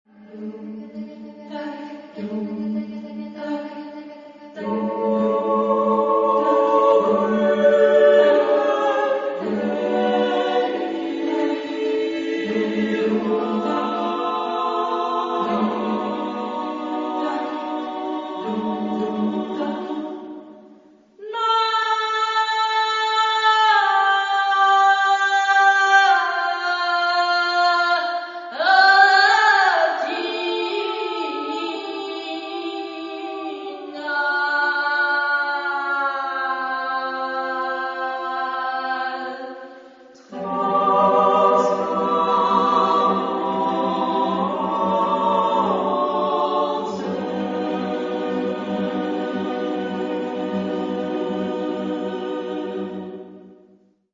Genre-Style-Forme : Profane ; Poème ; contemporain
Type de choeur : SMA  (3 voix égales OU égales de femmes )
Solistes : Soprano (2) / Alto (1)  (3 soliste(s))
Tonalité : plurimodal